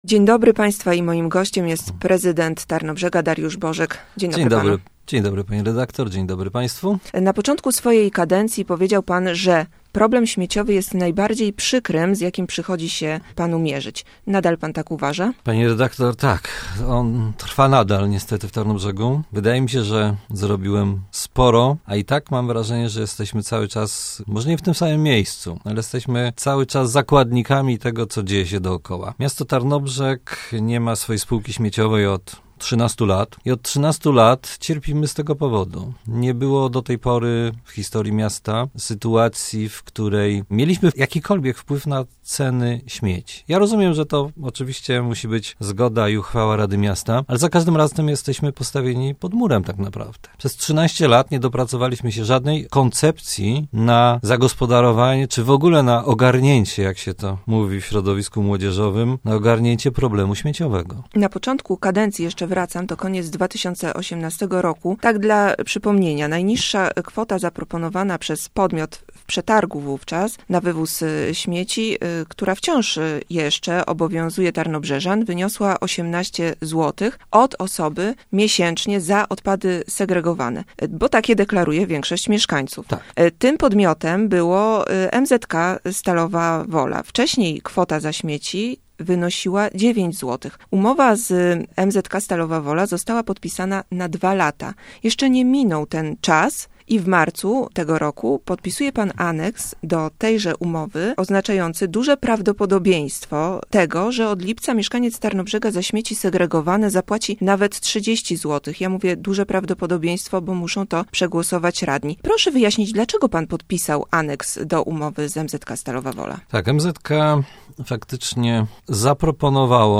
O cenach za odbiór odpadów komunalnych. Rozmowa z prezydentem Tarnobrzega, Dariuszem Bożkiem.